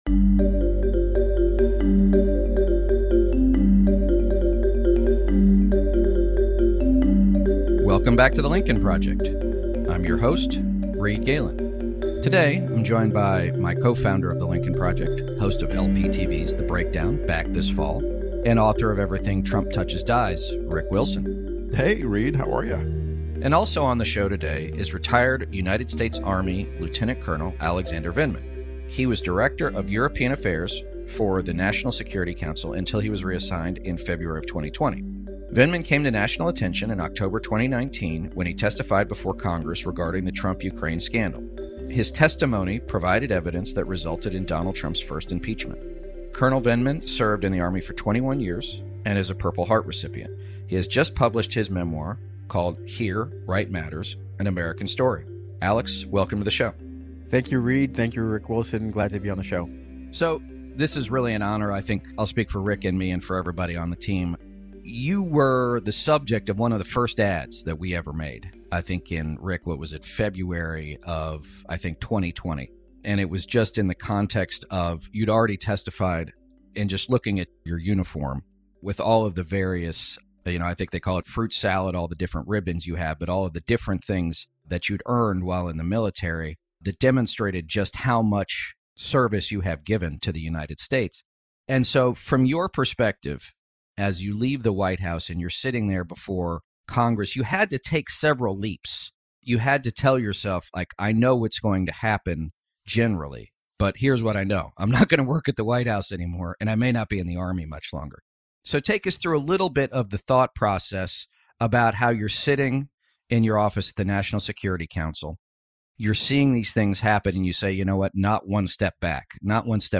Host Reed Galen is joined by fellow Lincoln Project Co-Founder Rick Wilson and retired United States Army Lieutenant Colonel Alexander Vindman who was the former Director for European Affairs for the United States National Security Council.